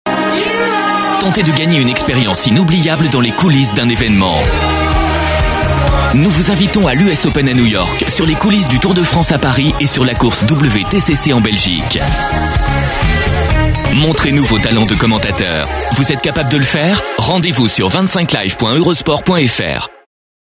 EUROSPORT 25 ans - Comédien voix off
Genre : voix off.